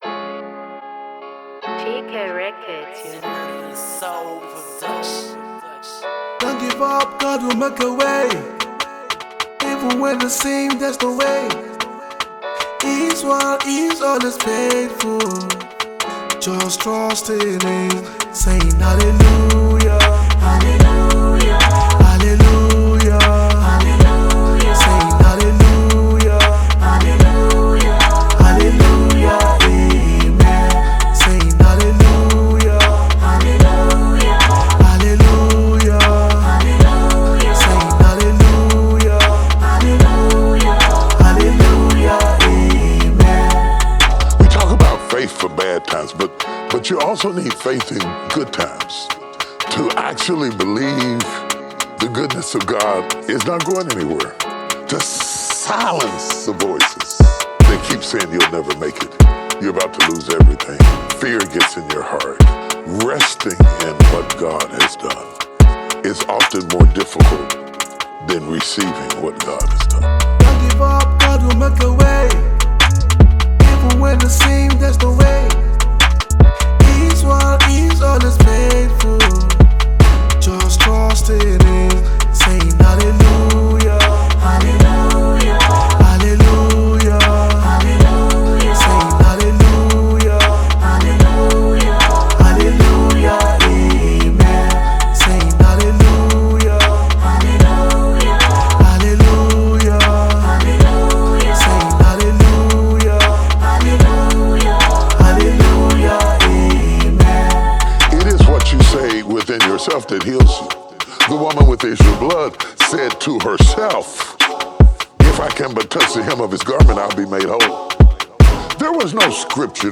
Amapiano Gospel
Genre: Gospel/Christian.